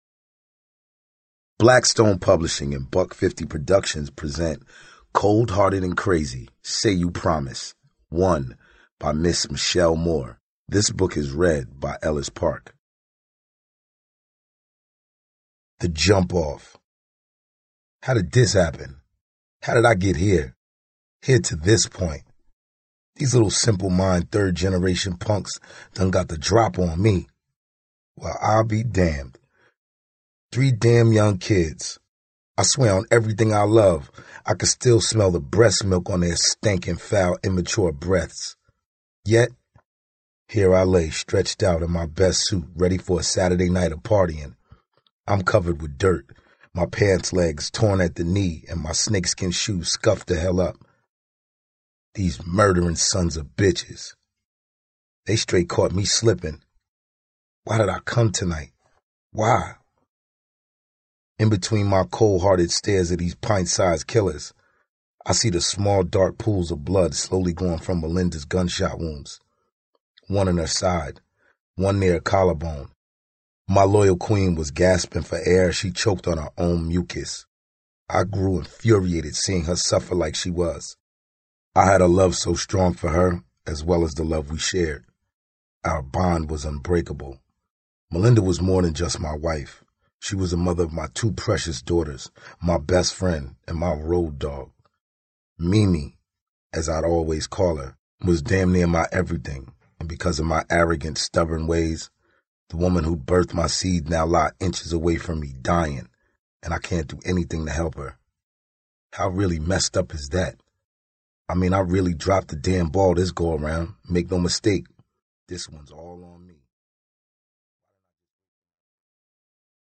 Spoken word.
Género: Audiobooks.